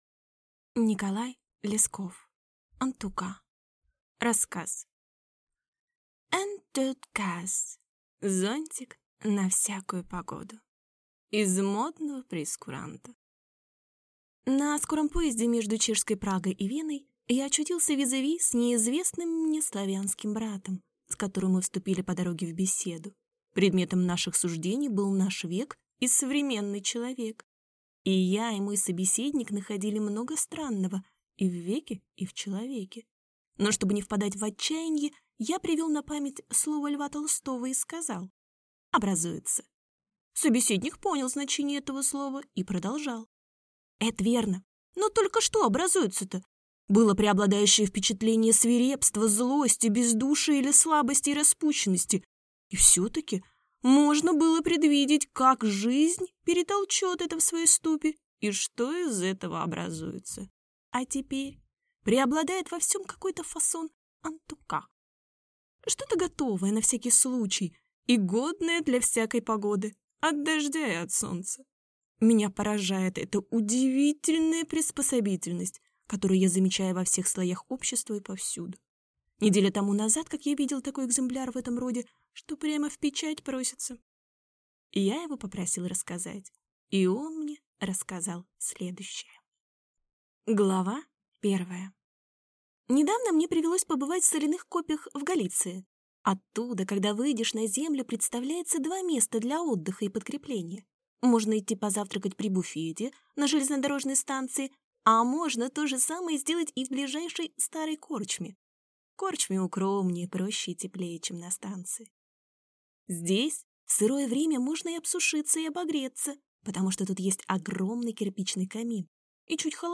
Аудиокнига Антука | Библиотека аудиокниг